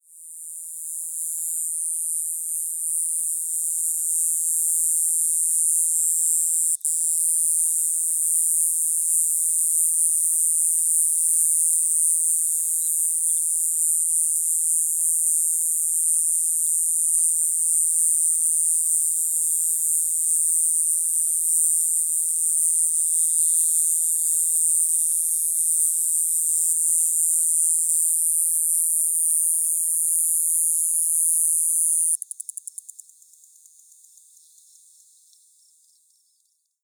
• Song is a continuous buzz, usually ending with several ticks.
• Calls from low on herbs and small shrubs.